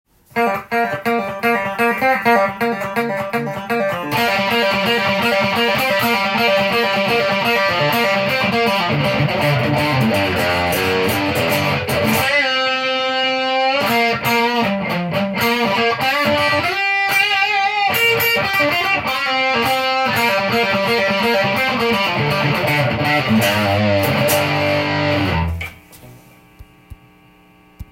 あまり歪まないのかな？と思いきや結構ガッツリした音がします！